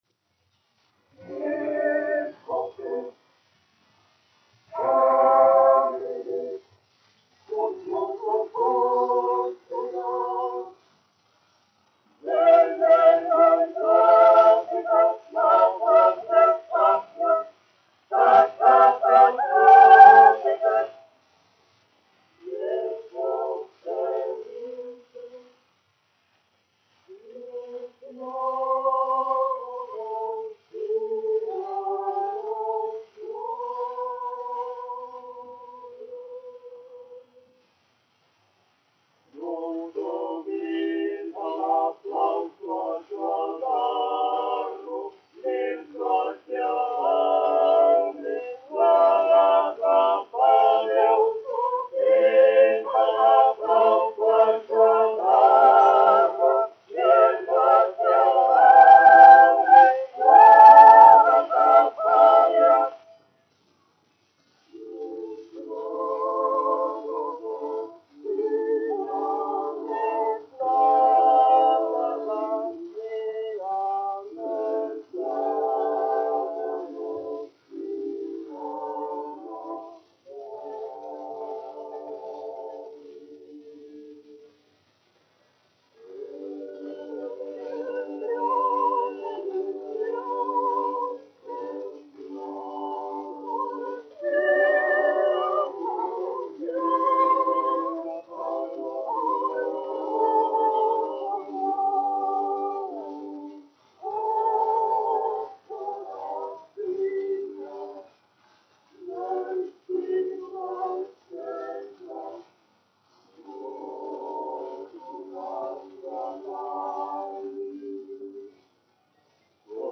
Rīgas Latviešu dziedāšanas biedrības jauktais koris, izpildītājs
1 skpl. : analogs, 78 apgr/min, mono ; 25 cm
Kori (jauktie)
Skaņuplate